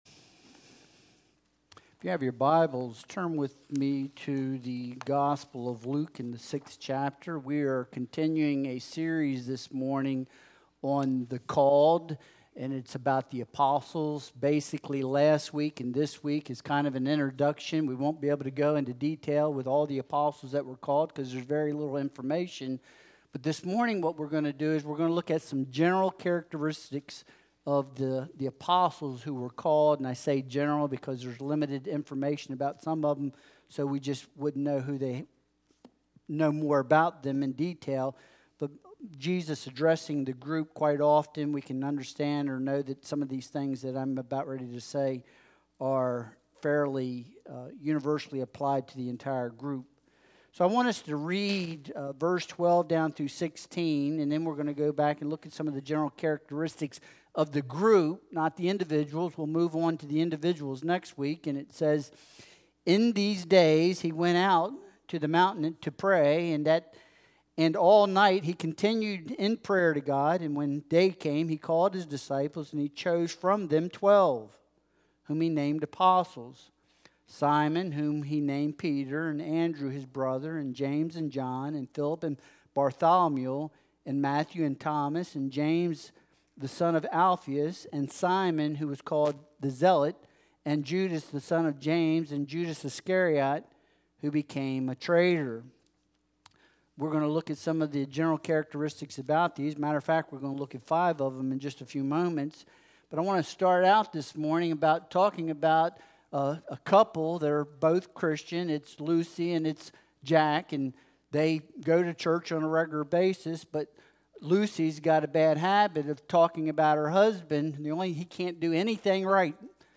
Luke 6.12-16 Service Type: Sunday Worship Service Download Files Bulletin « Why Them?